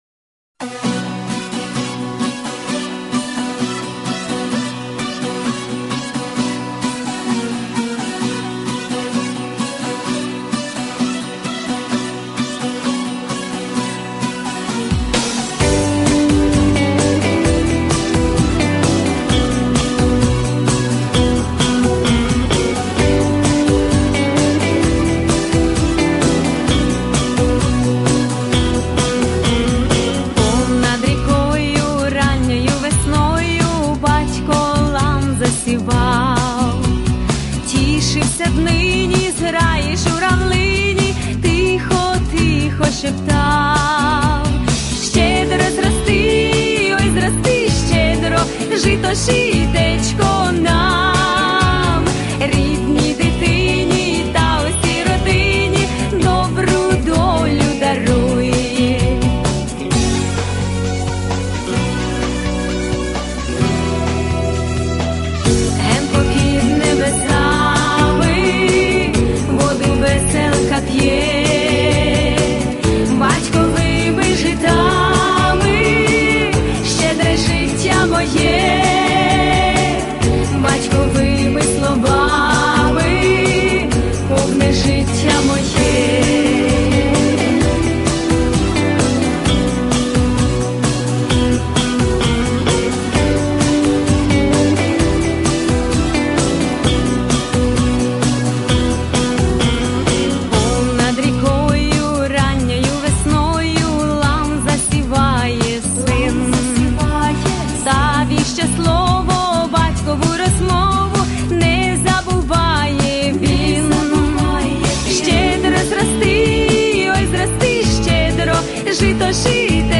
Помірна
Соло
Жіноча
Плюсовий запис